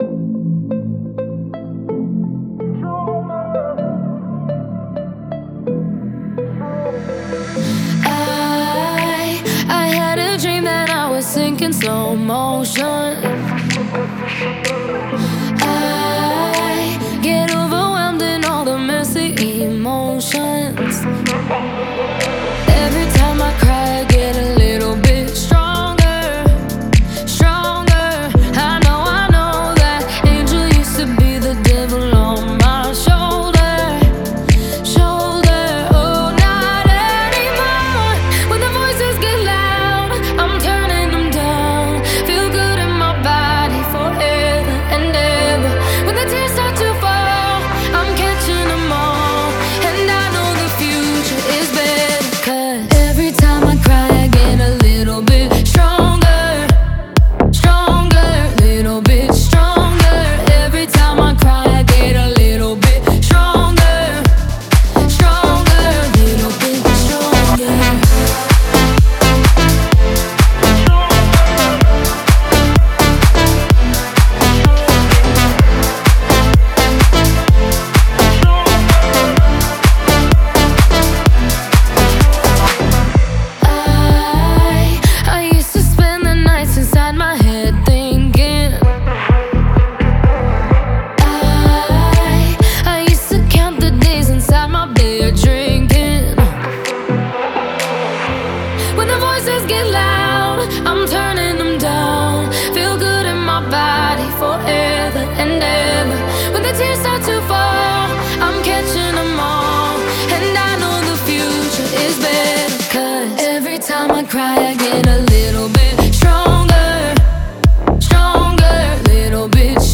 мощного вокала